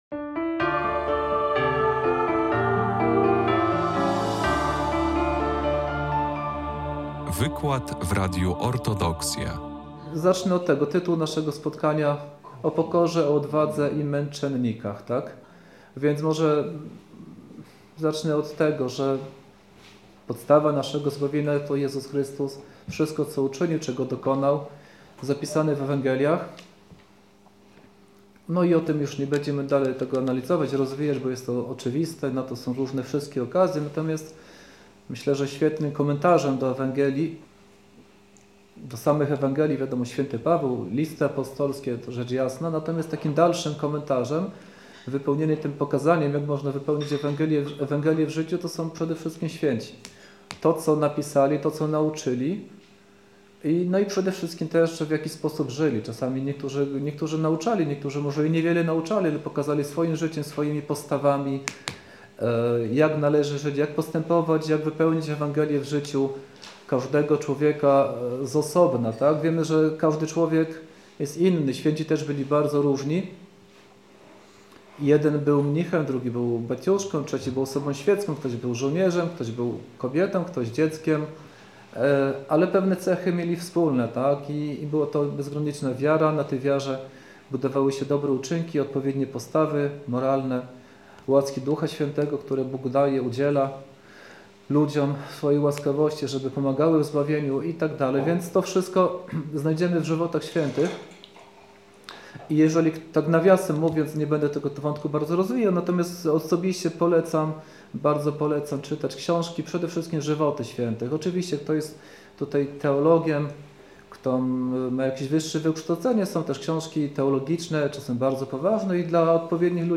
wykład